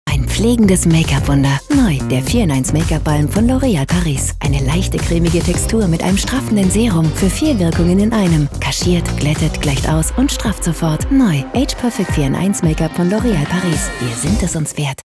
Stimme für Deine Tagescreme, wenn die ersten Fältchen kommen, Stimmalter 30-40 Jahre , die Coole, die Dein Haar stylt oder Deine
FEMALE GERMAN VOICE ARTIST and Voice Actress
My personal recording studio allows your audio file to be recorded very easily and guarantees the highest audio quality.